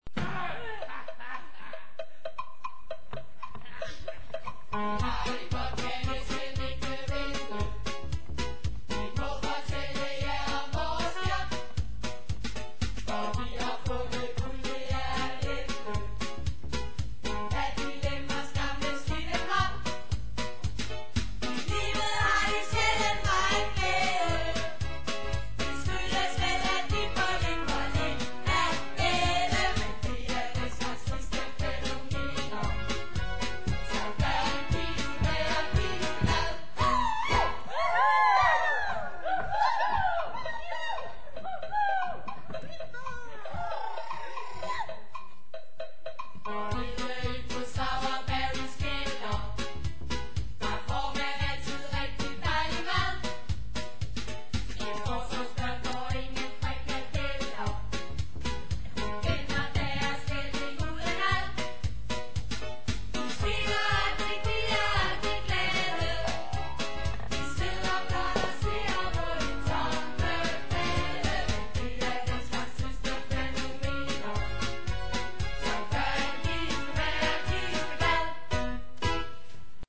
Vi hører det ene store svingende nummer efter det andet.
"live on stage!"